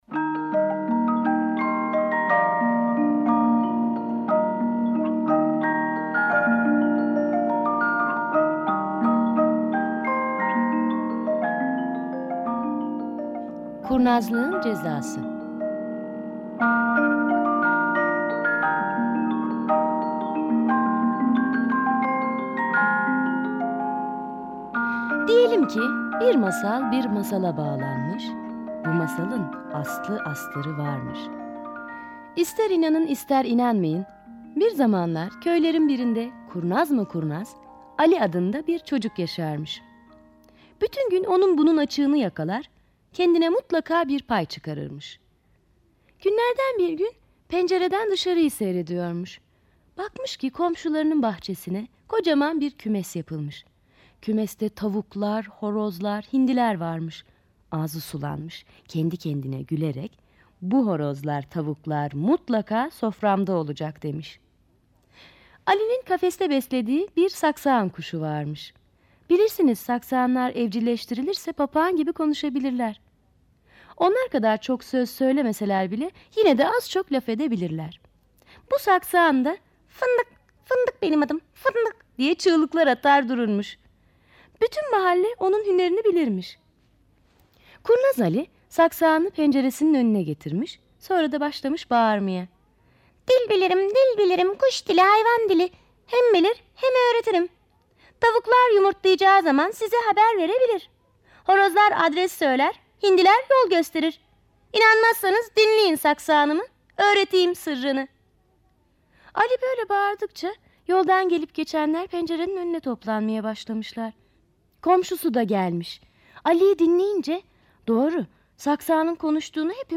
Sesli Çocuk Masalları